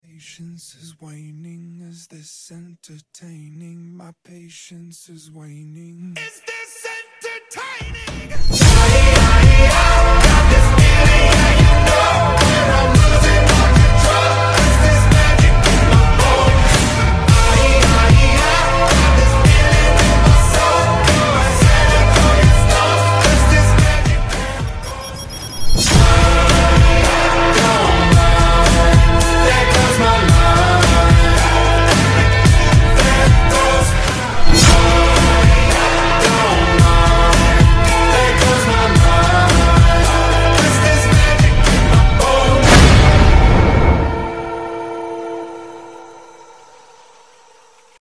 Im so sorry for the quality idk what happened 🥶